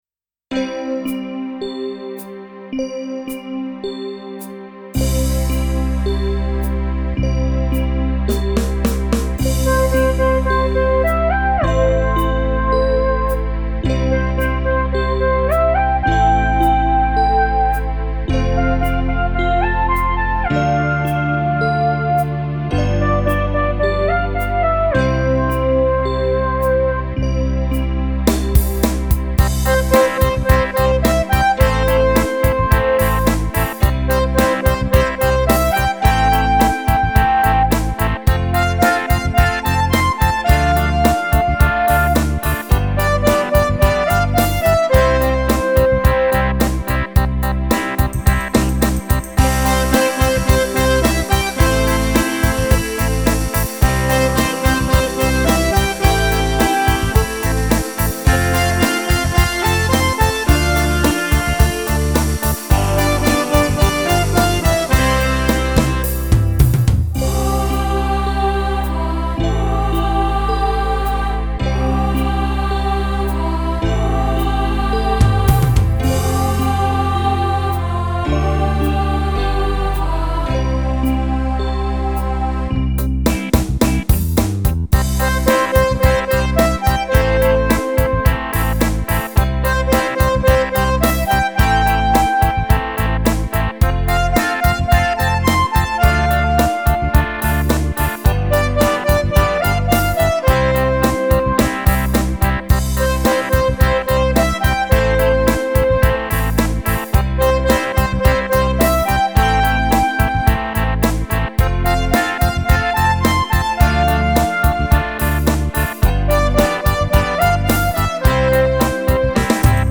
Style nach dem NDW-Hit